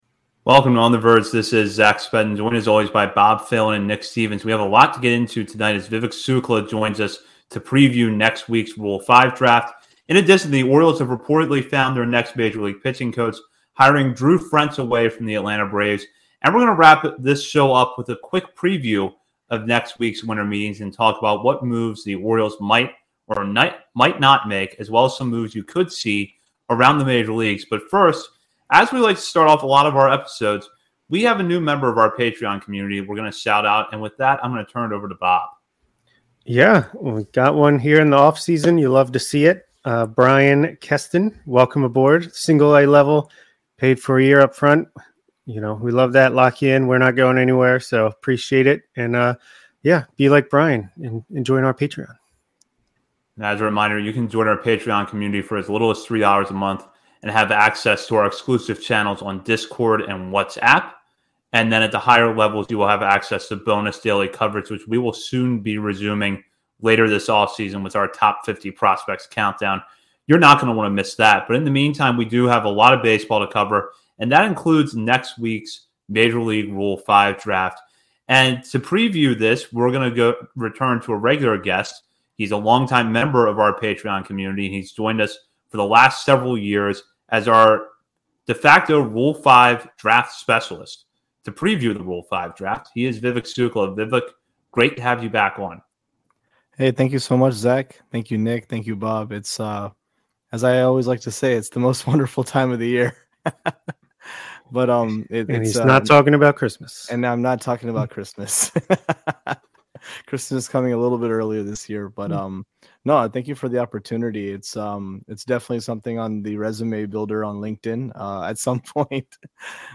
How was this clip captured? Recorded live on March 29, 2026 at Checkerspot Brewery before the game against the Twins.